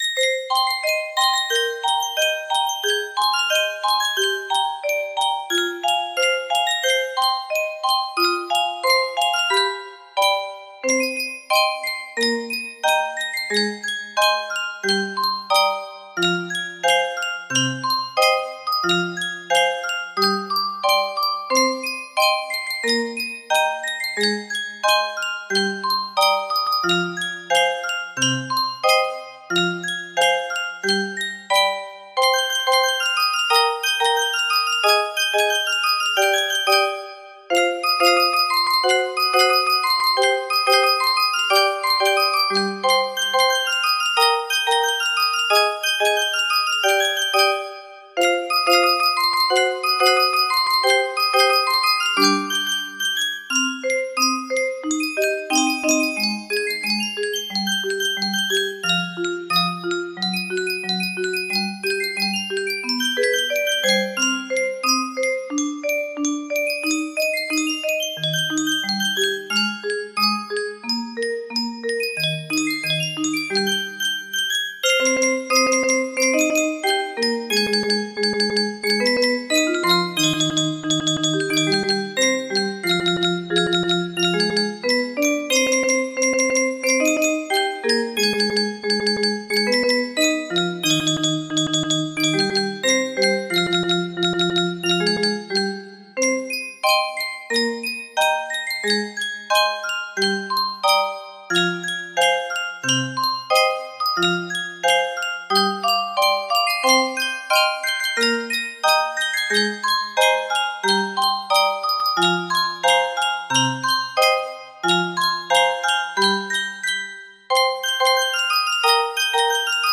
Full range 60
A full range sixty, 979 length, homemade version